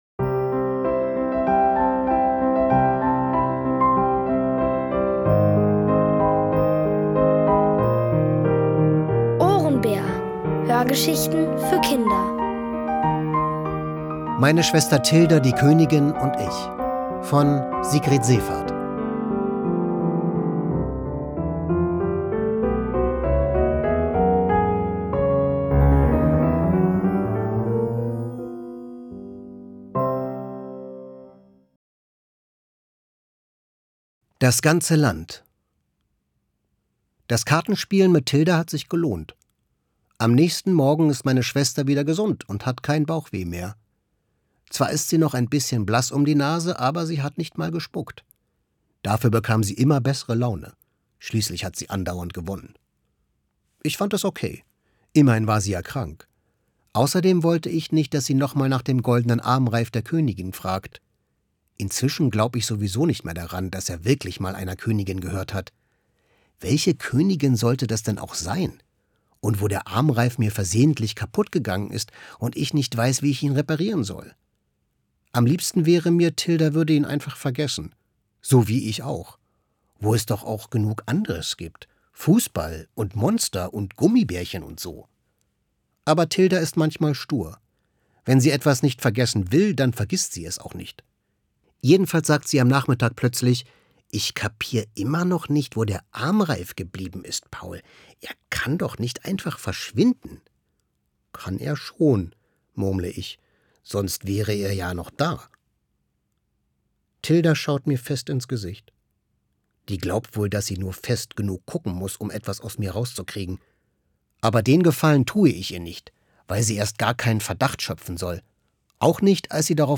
Von Autoren extra für die Reihe geschrieben und von bekannten Schauspielern gelesen.
(Folge 5 von 7) von Sigrid Zeevaert. Es liest: Florian Lukas.